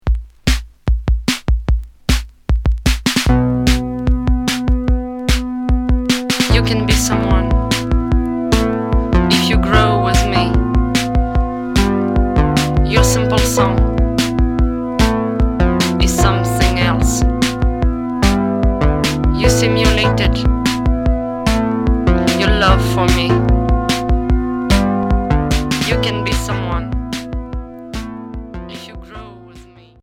Minimal synth Réédition du premier 45t retour à l'accueil